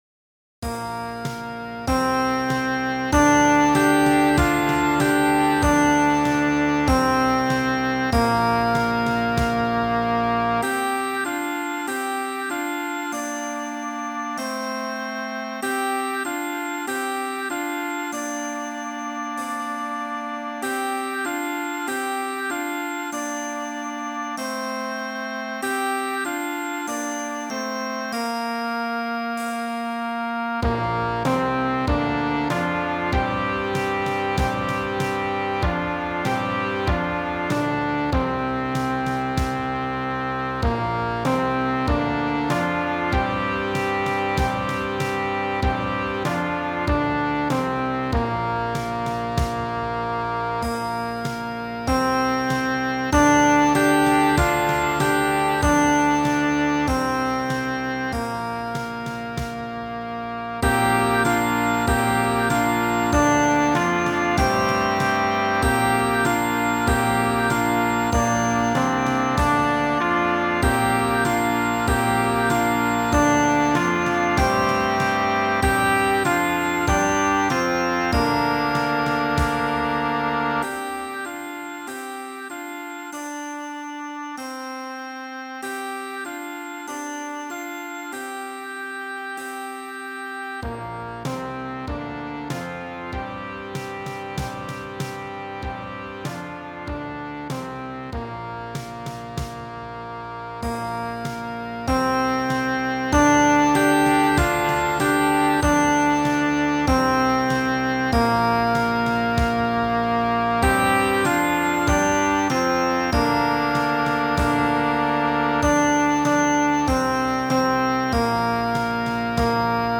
Flute
Oboe
Alto Saxophone
Horn in F
Low Brass and Woodwinds
Mallet Percussion
Timpani
Percussion 1 (Snare Drum, Bass Drum)
Percussion 2 (Triangle, Suspended Cymbal)